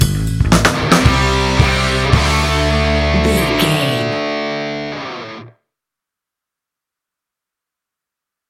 Ionian/Major
hard rock
distortion
instrumentals